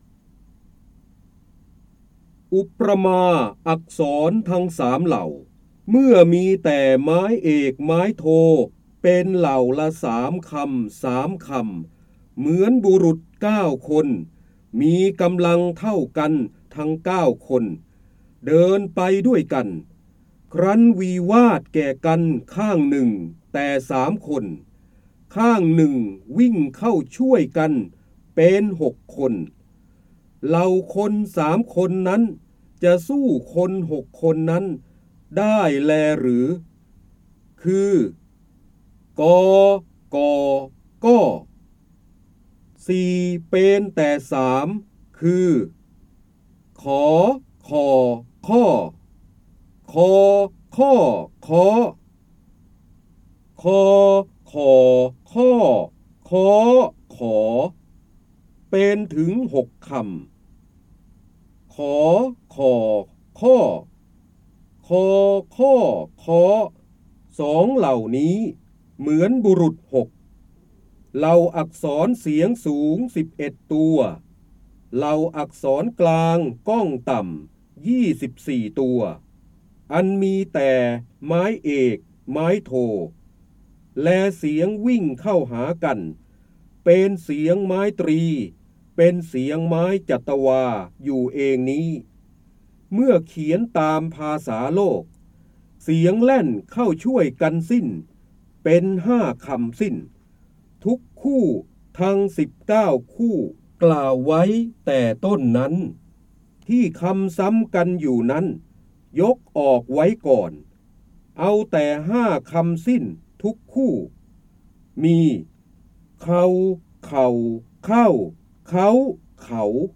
เสียงบรรยายจากหนังสือ จินดามณี (พระเจ้าบรมโกศ) อุประมาอักษร
คำสำคัญ : พระโหราธิบดี, ร้อยแก้ว, พระเจ้าบรมโกศ, การอ่านออกเสียง, จินดามณี, ร้อยกรอง
ลักษณะของสื่อ :   คลิปเสียง, คลิปการเรียนรู้